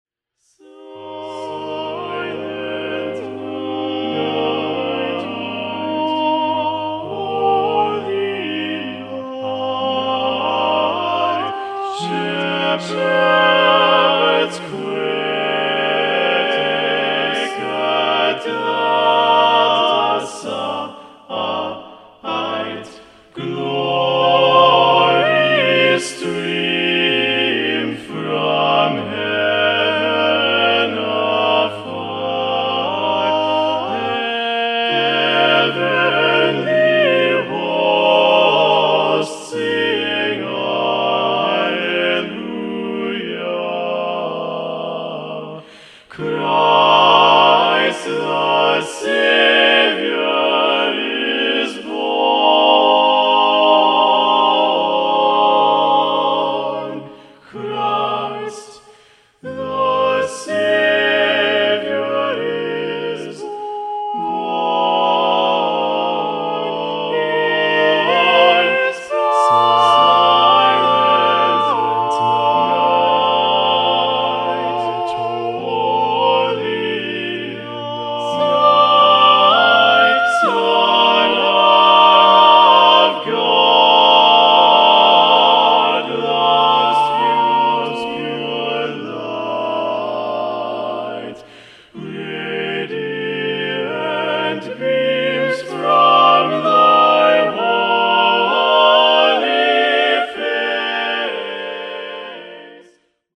Choeur Mixte (SATB) a Cappella